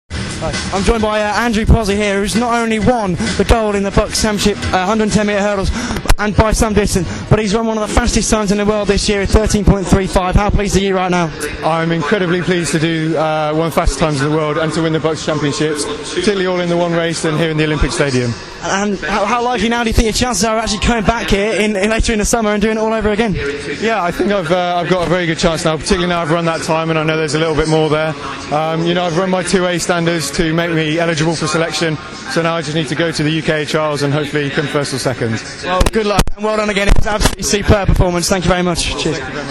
Andrew Pozzi Interview
Pozzie speaks after a storming performance